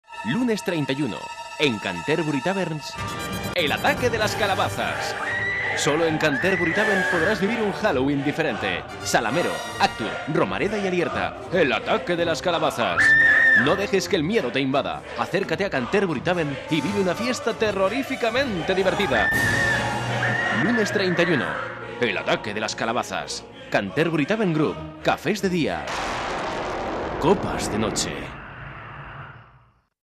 Sprecher spanisch für Werbung, Industrie, Imagefilme, E-Learning etc
Sprechprobe: eLearning (Muttersprache):
spanish voice over talent